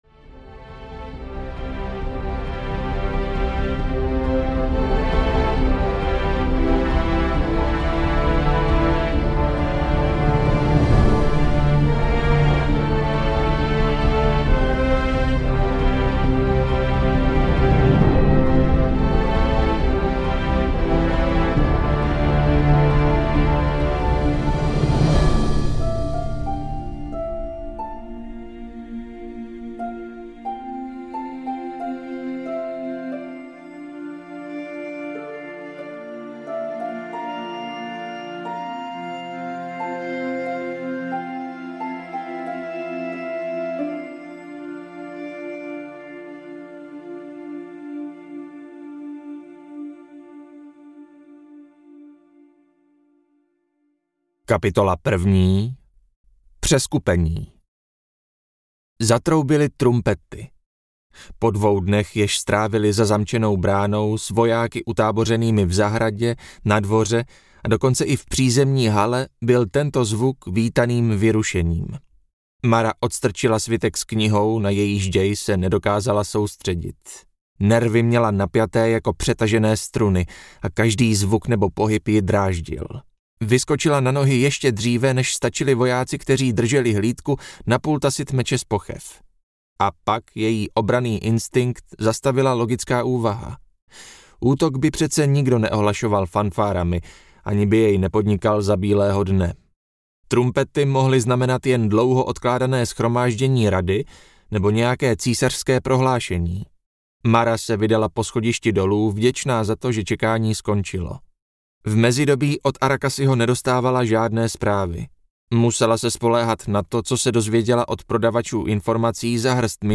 Ochránce impéria - Milenec audiokniha
Ukázka z knihy